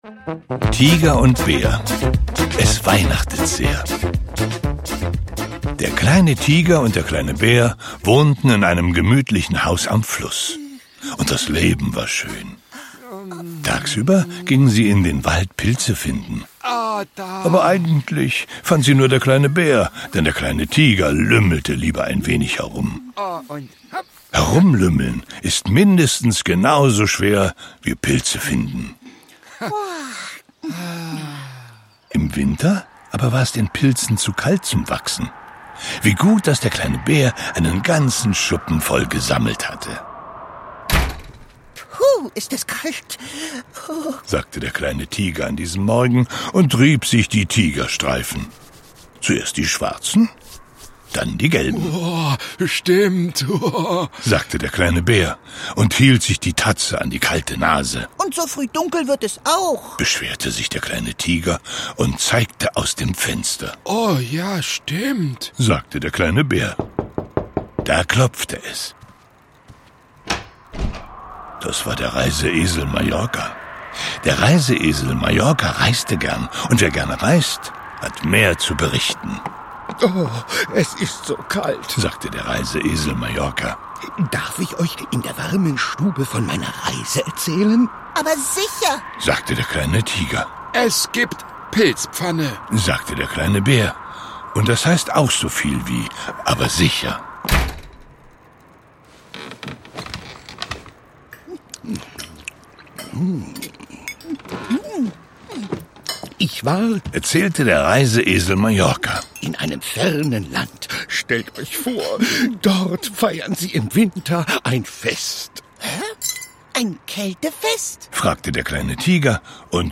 Hörbuch: Nach einer Figurenwelt von Janosch.